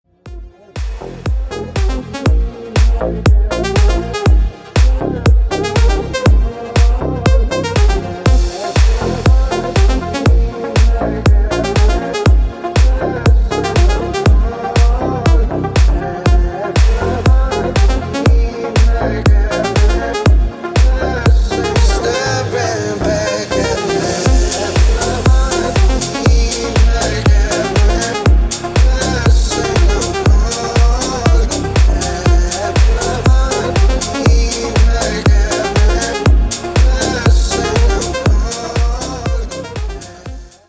мужской голос
deep house
Electronic
EDM
качающие
клубняк
Стиль: deep house